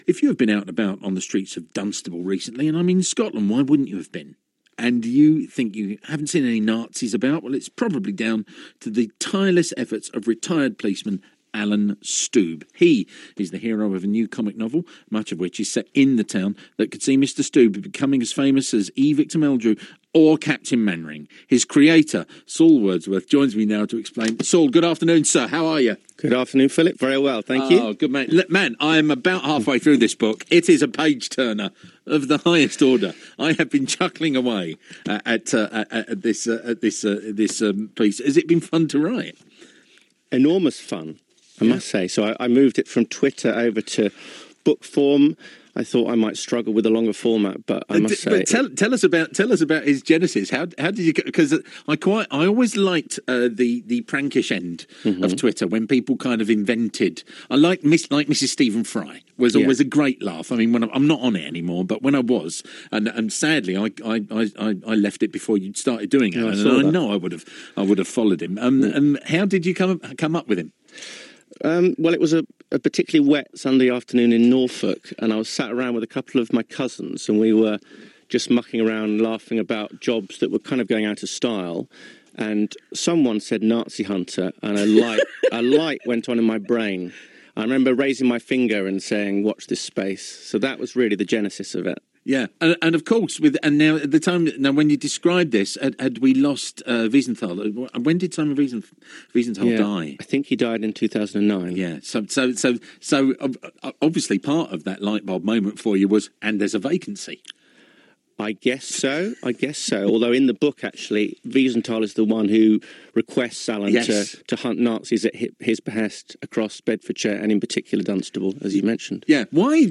Here’s a BBC radio interview I did with comedian Phil Jupitus. https